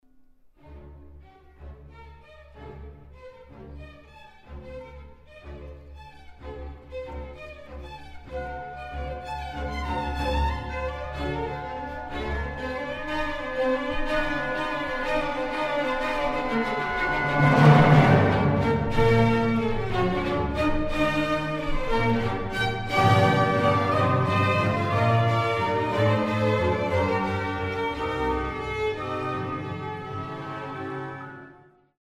Example 1 – Opening Allegro: